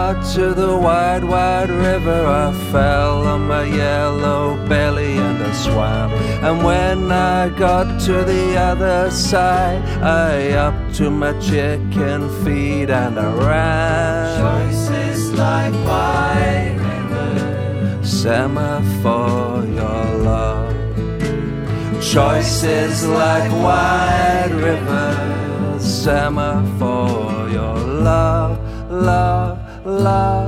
enregistré et mixé en Suède